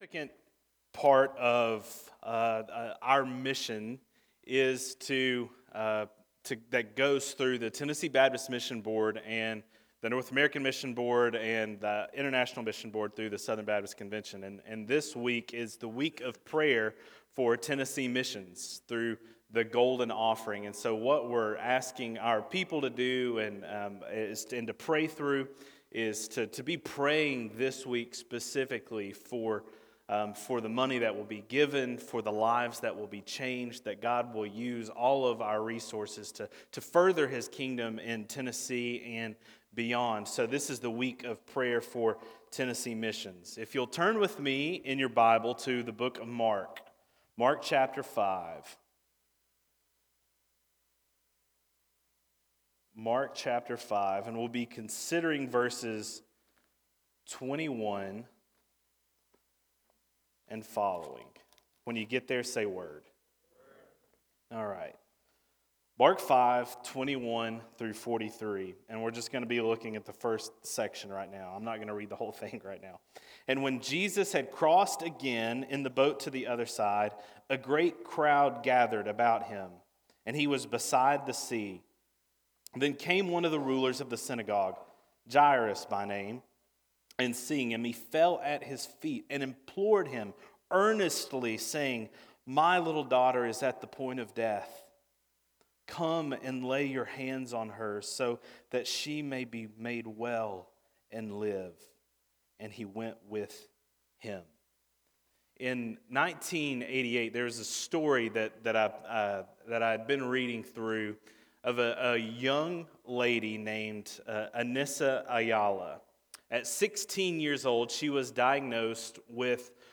Sermons › Mark 5:21-43 – Signposts